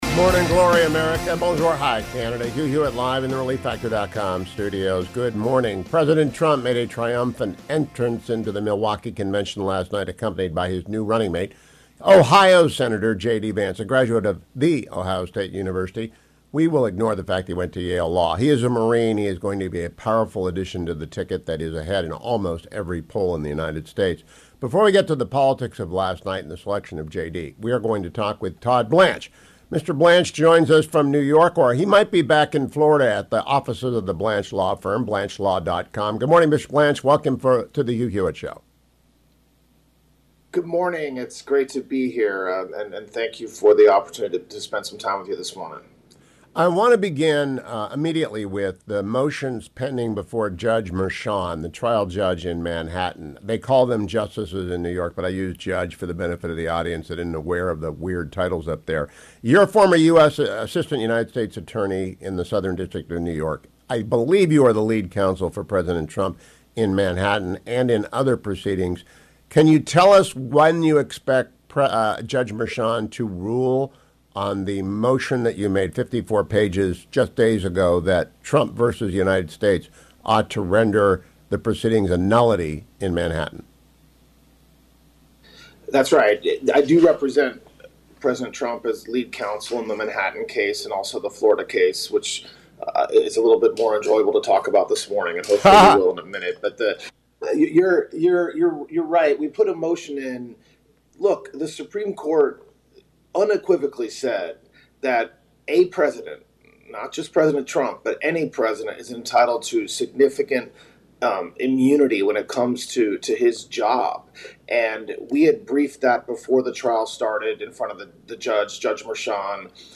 Todd Blanche, former President Trump’s lawyer in Manhattan, joined me this AM: